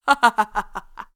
Female laugh